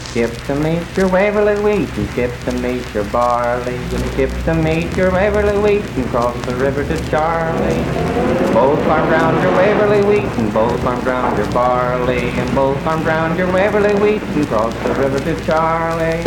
Unaccompanied vocal performance
Dance, Game, and Party Songs
Voice (sung)
Spencer (W. Va.), Roane County (W. Va.)